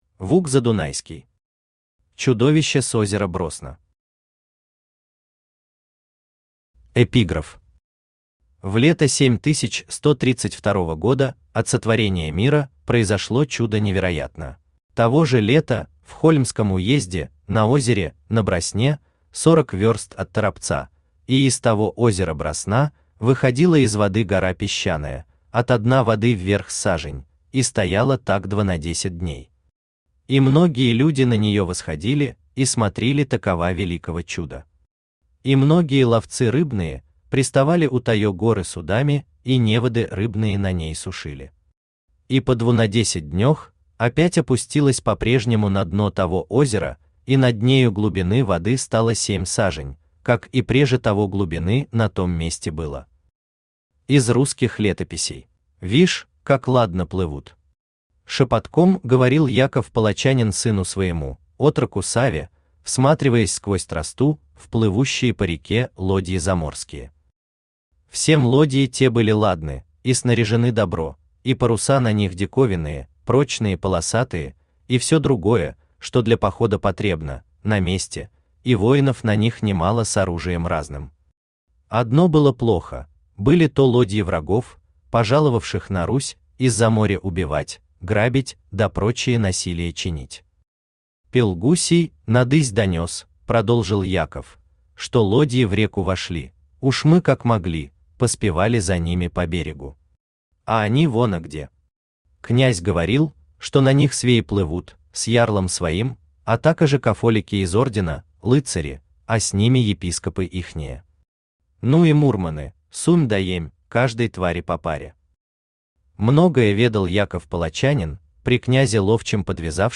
Aудиокнига Чудовище с озера Бросно Автор Вук Задунайский Читает аудиокнигу Авточтец ЛитРес.